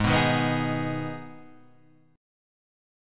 G#m chord {4 2 1 4 4 4} chord